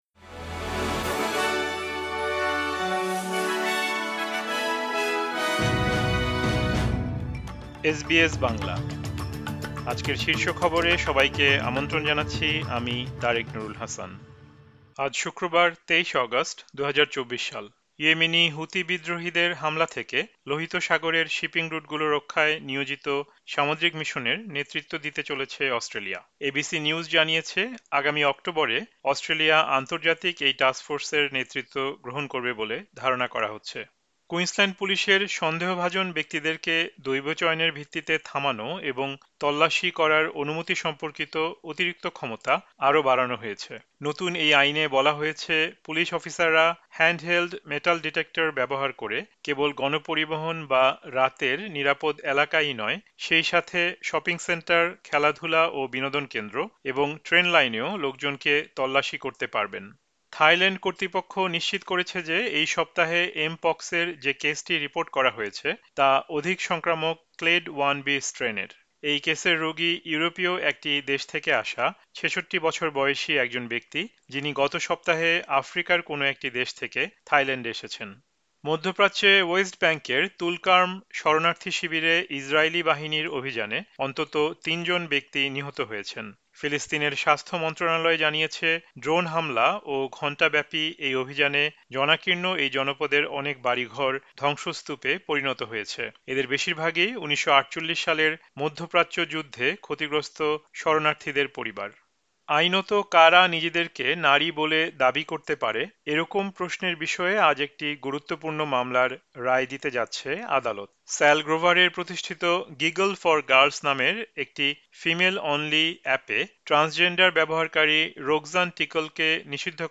এসবিএস বাংলা শীর্ষ খবর: ২৩ অগাস্ট, ২০২৪